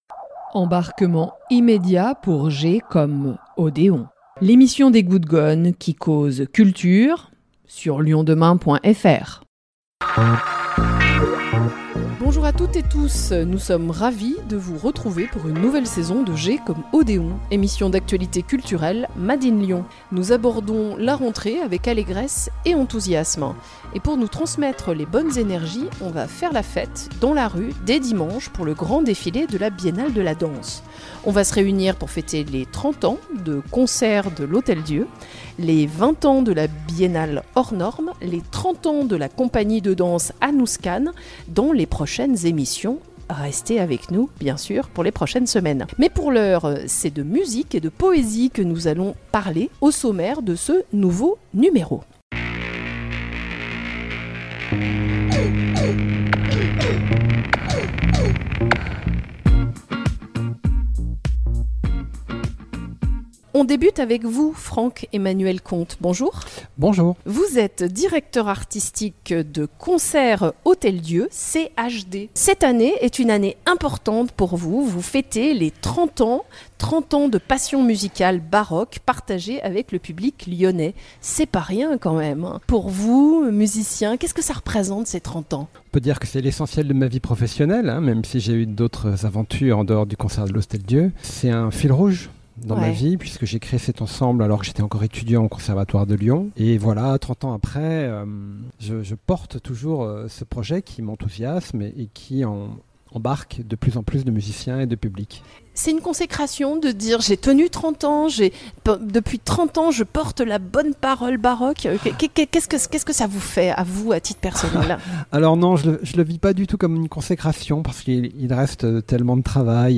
C'est la rentrée pour G comme Odéon, émission d’actualité culturelle « made in Lyon » !